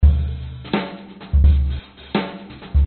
标签： experimental 085bpm noise loop metaphysically ambient processed melodic
声道立体声